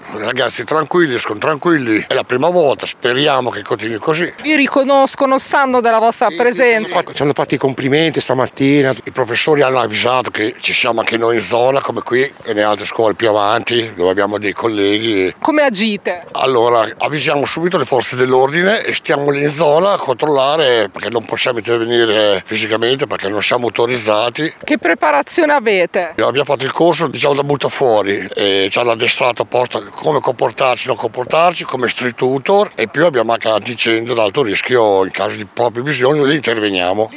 Siamo stati davanti al Polo Leonardo, sentiamo i due school tutor presenti: